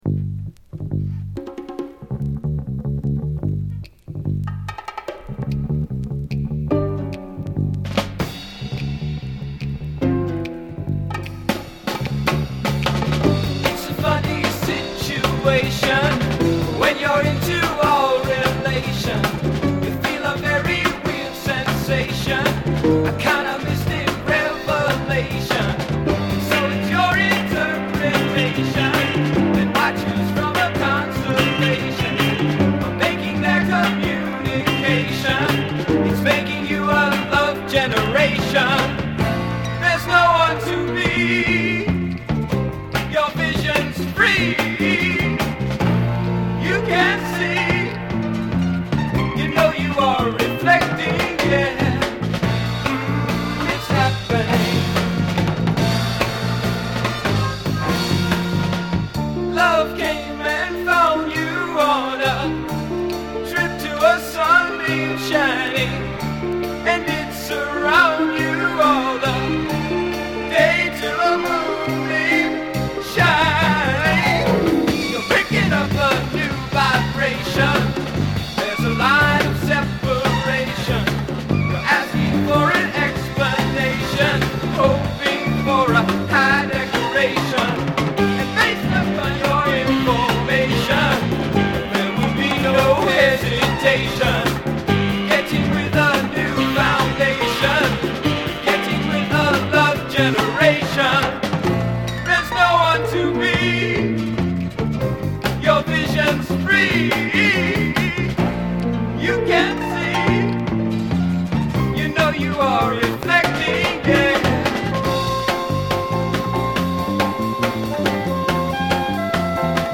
psych-pop
“latin percussion”